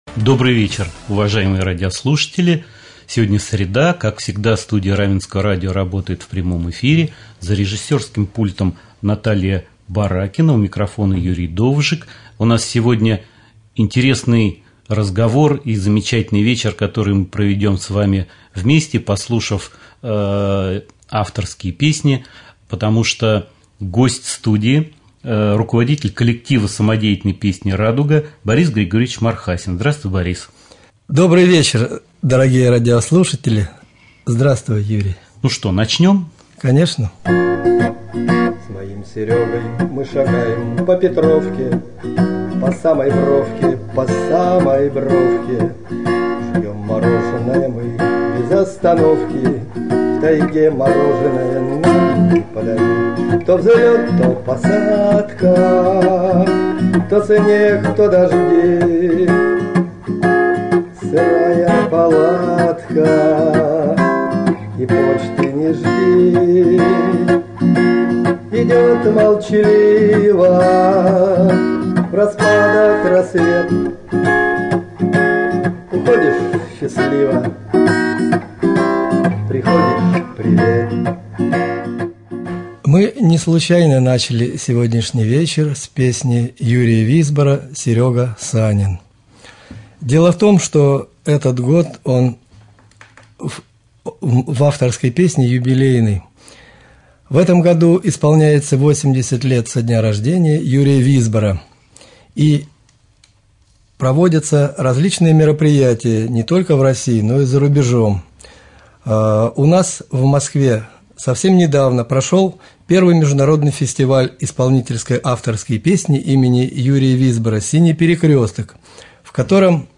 2.Прямой-эфир.mp3